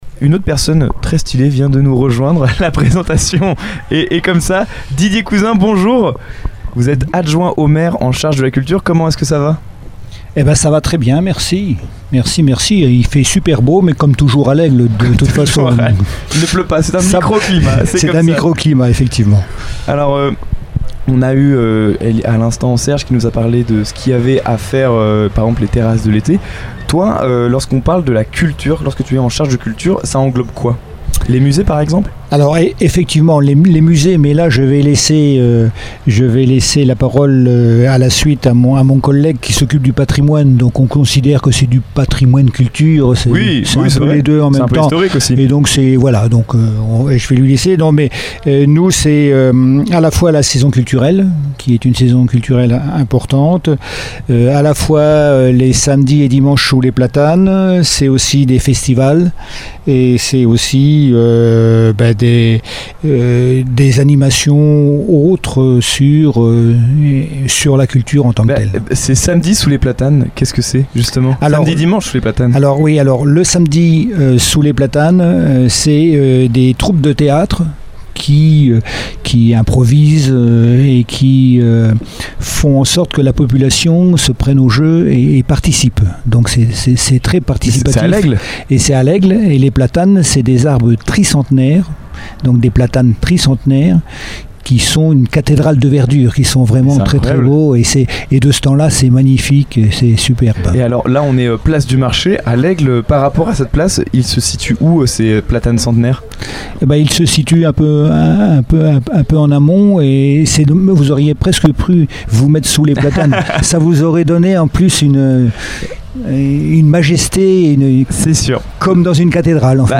Une interview passionnante qui met en lumière le dynamisme culturel de L’Aigle et l’engagement municipal en faveur du patrimoine et de la création artistique.